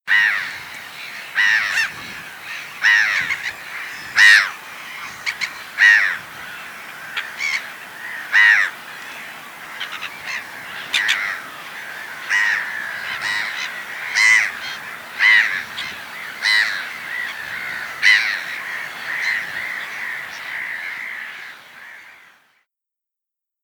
kokmeeuw
🔭 Wetenschappelijk: Larus ridibundus
♪ contactroep filmpje 2021
kokmeeuw_roep.mp3